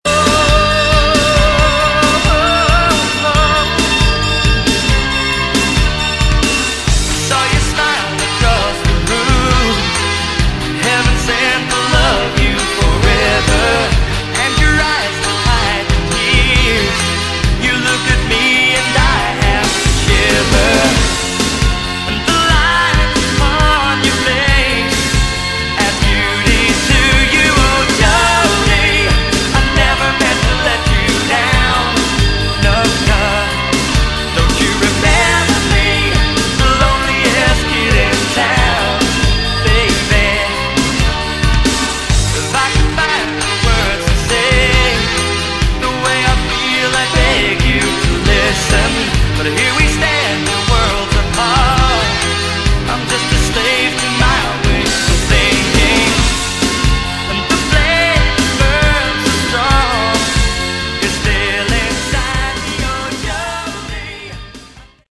Category: Melodic Rock
Second CD consists of unreleased demos.